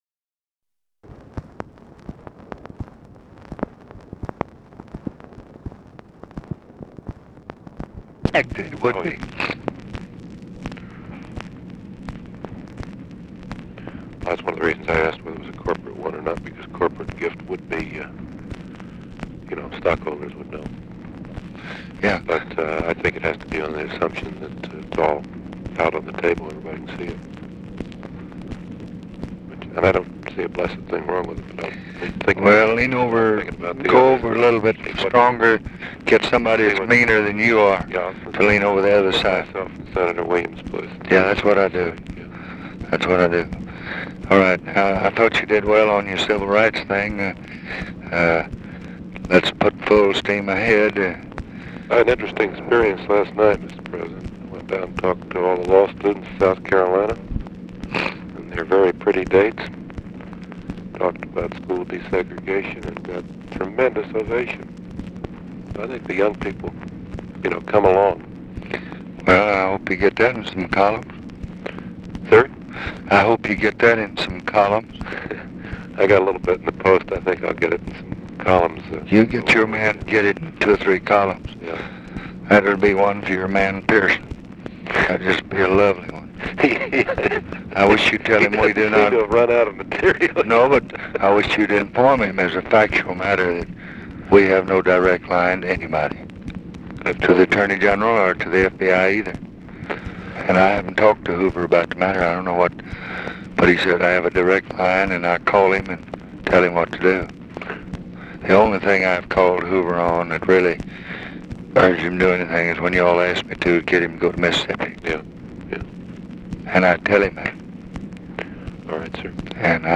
Conversation with NICHOLAS KATZENBACH, April 29, 1966
Secret White House Tapes